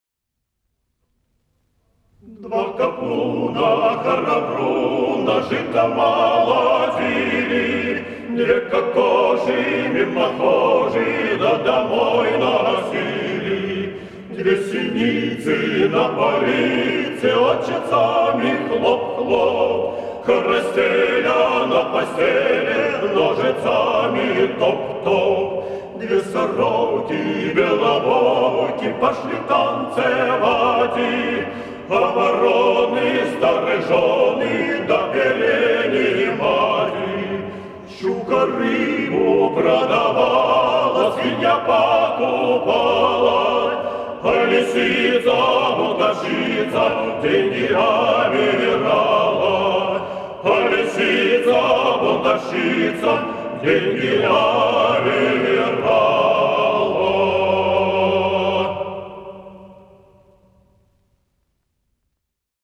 Шуточные
Для кантов характерно трехголосие гомофонного склада.